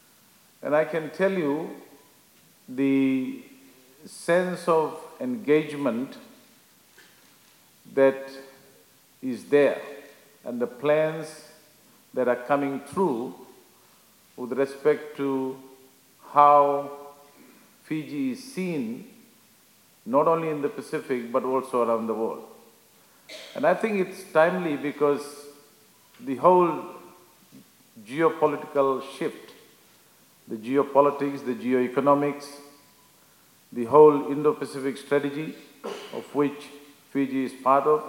The two ministers were speaking at a Fiji Employers and Commerce Federation breakfast event.